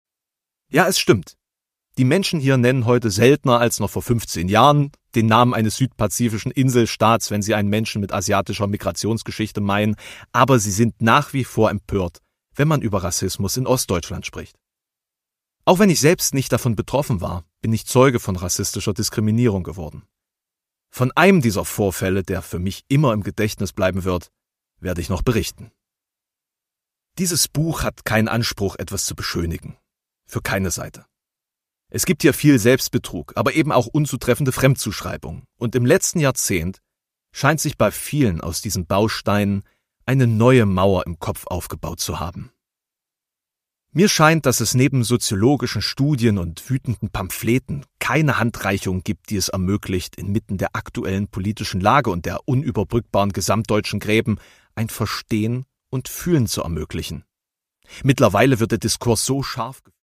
Produkttyp: Hörbuch-Download
Gelesen von: Alexander Prinz
Das Hörbuch wird gelesen vom Autor.